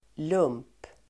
Uttal: [lum:p]